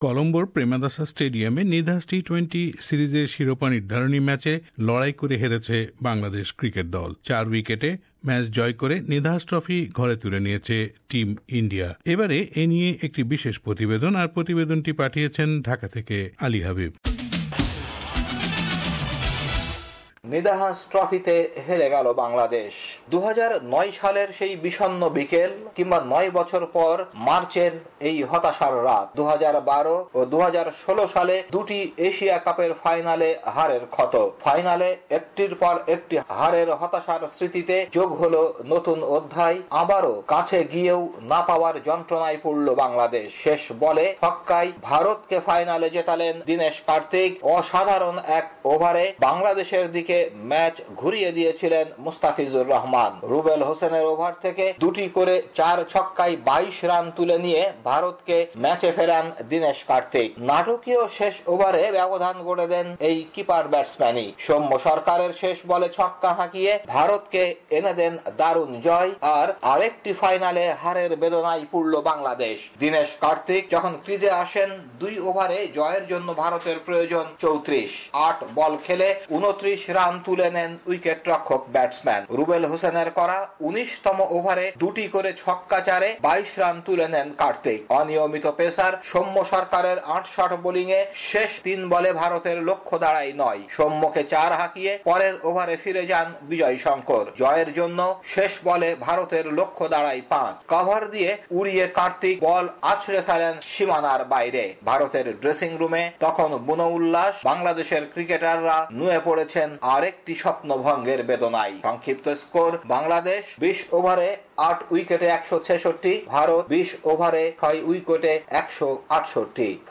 Nidahas Trophy Final Match Report; Interview with Aminul Islam Bulbul